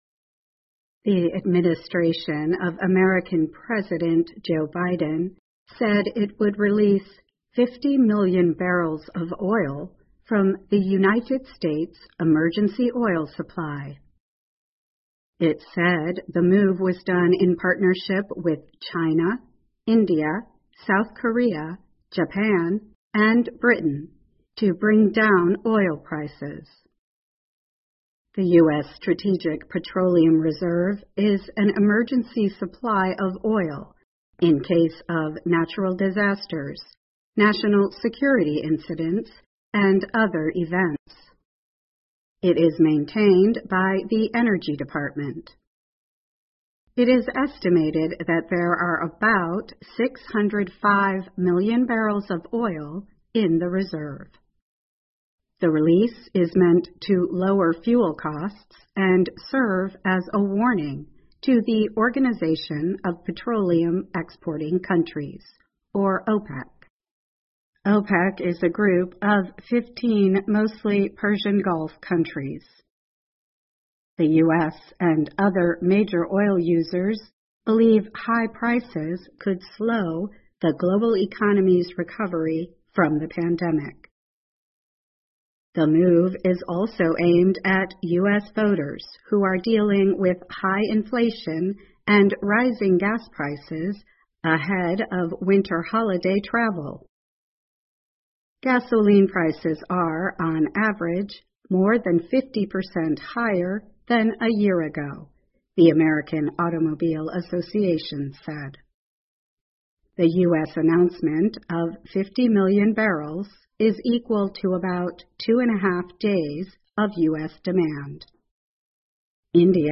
VOA慢速英语2021 美国释放战略石油储备以降低油价 听力文件下载—在线英语听力室